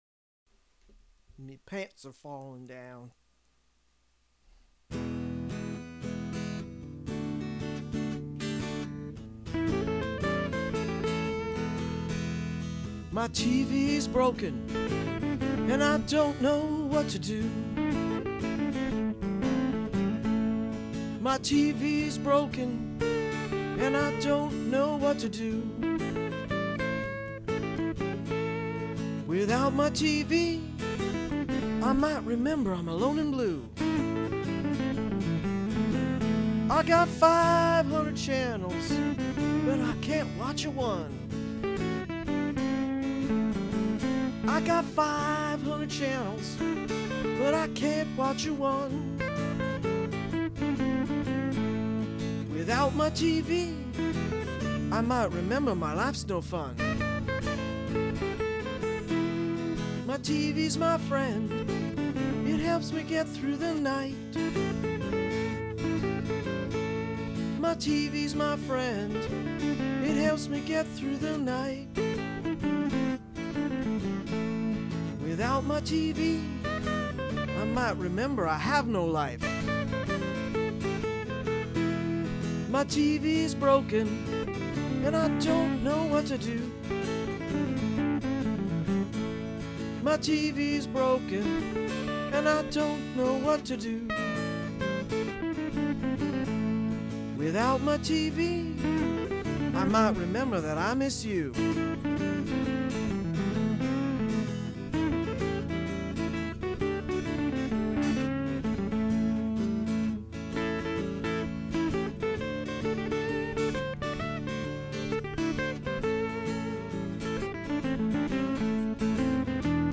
Recorded in Low-Fidelity December 2005-February 2006 at
Guitar
and  Drum Machine